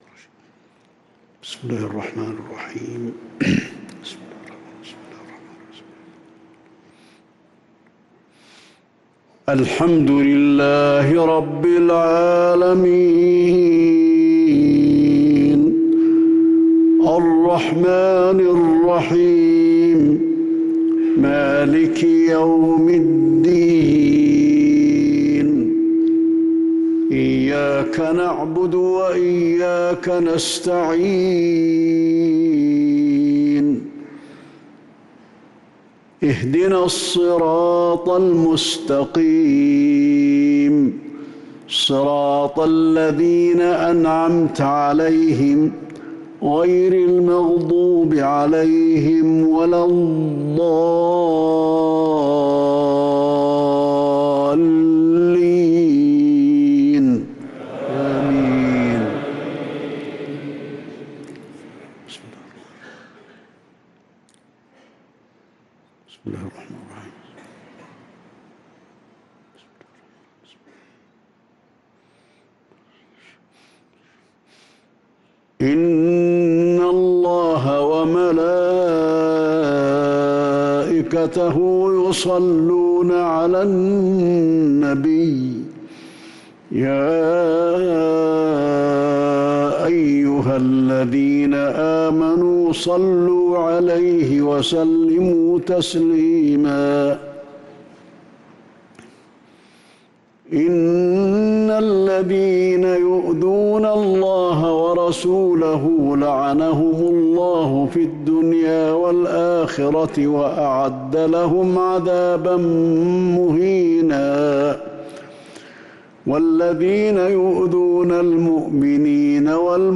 صلاة العشاء للقارئ علي الحذيفي 23 محرم 1445 هـ
تِلَاوَات الْحَرَمَيْن .